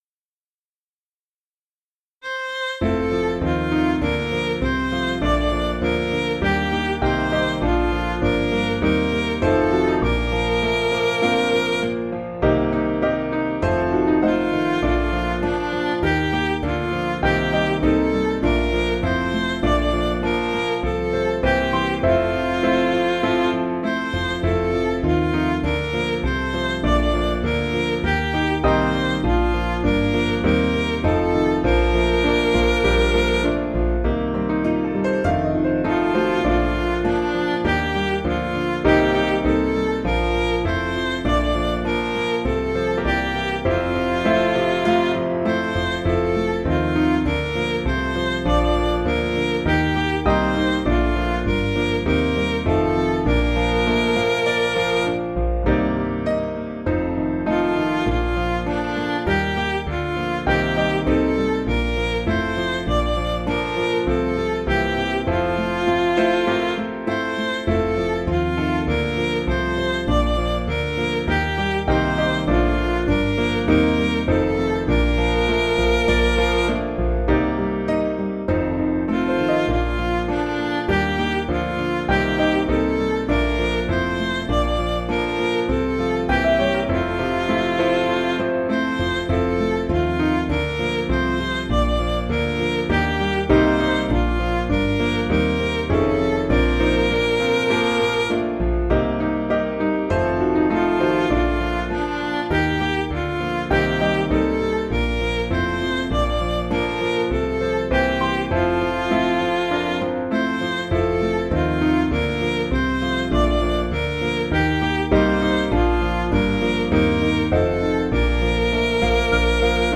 Piano & Instrumental
(CM)   6/Bb